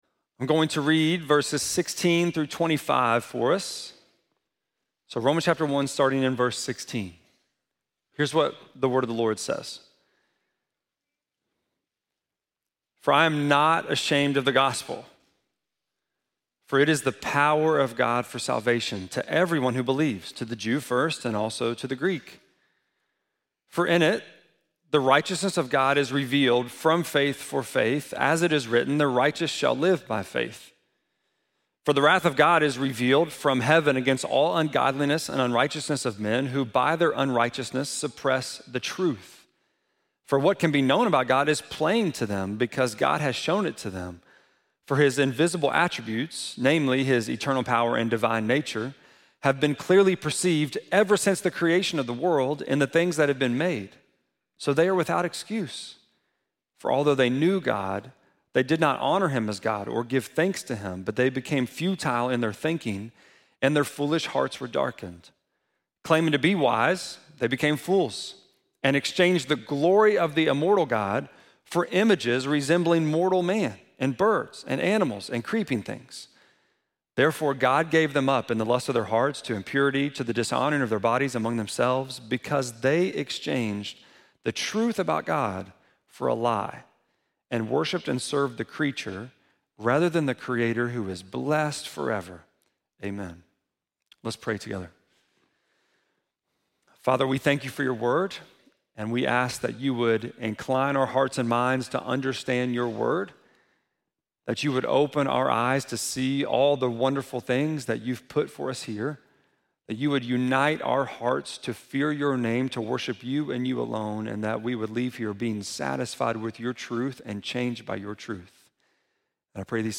6.1-sermon.mp3